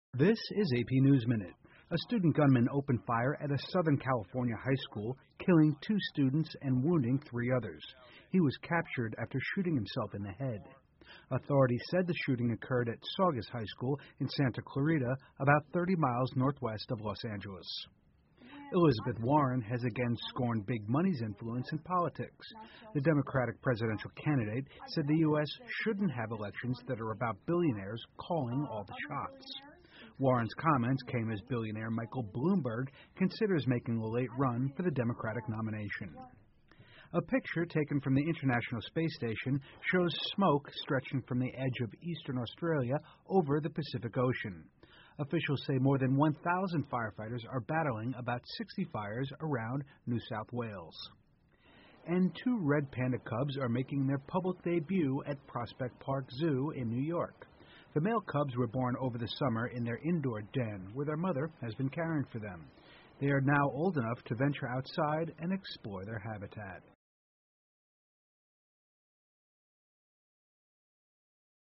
美联社新闻一分钟 AP 南加州一高中发生枪击案 听力文件下载—在线英语听力室